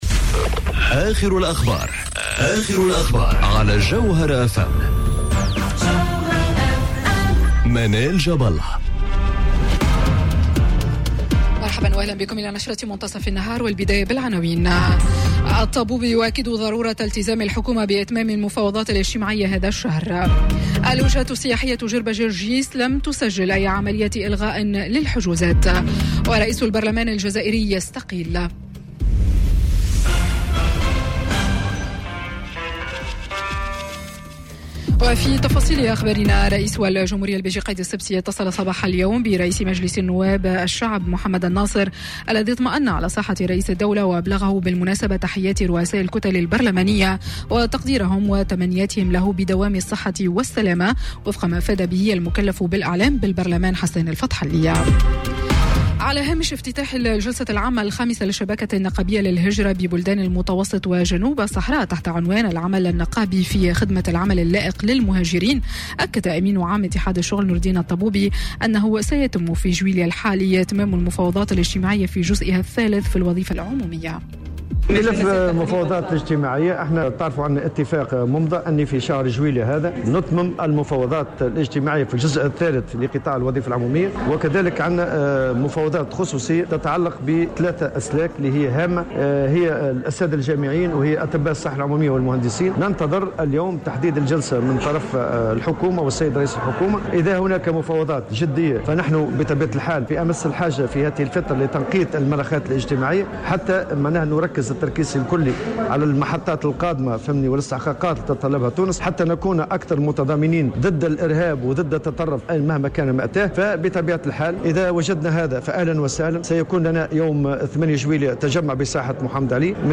نشرة أخبار متصف النهار ليوم الثلاثاء 02 جويلية 2019